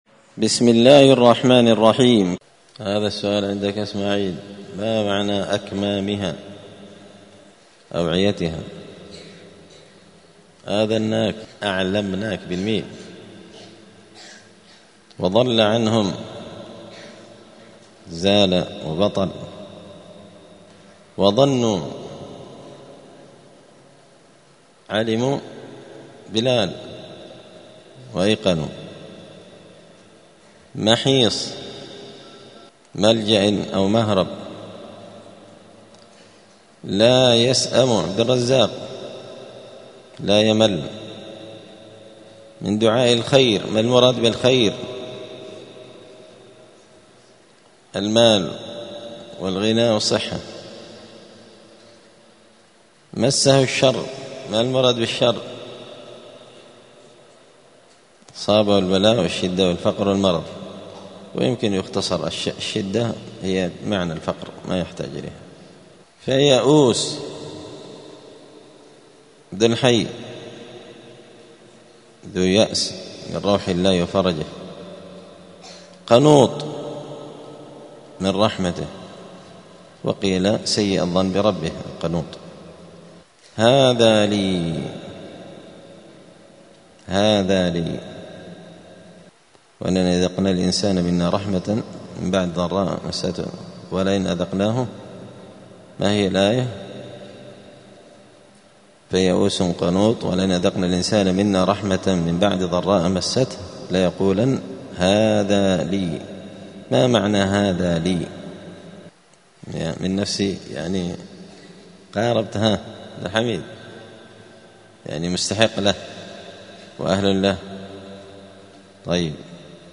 زبدة الأقوال في غريب كلام المتعال الدرس الثالث والعشرون بعد المائتين (223)
دار الحديث السلفية بمسجد الفرقان قشن المهرة اليمن